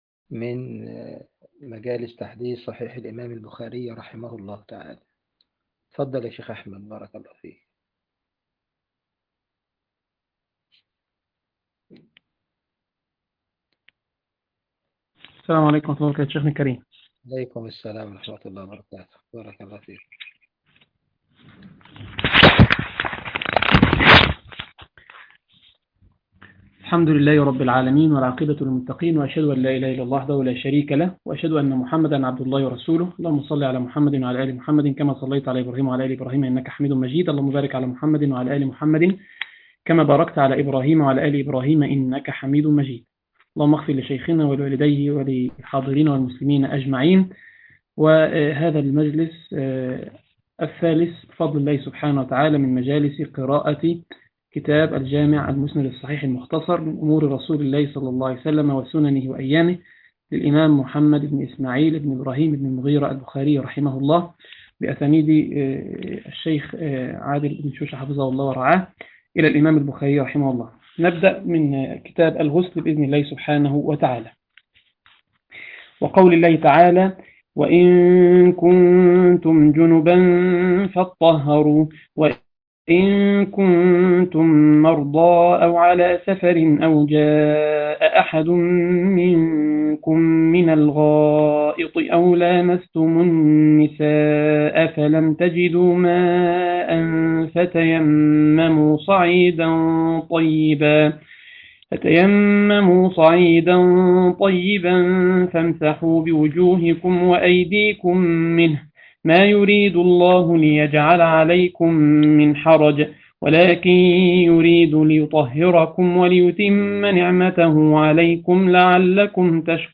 تفاصيل المادة عنوان المادة ٣- قراءة صحيح البخاري تاريخ التحميل الأربعاء 15 مارس 2023 مـ حجم المادة غير معروف عدد الزيارات 317 زيارة عدد مرات الحفظ 161 مرة إستماع المادة حفظ المادة اضف تعليقك أرسل لصديق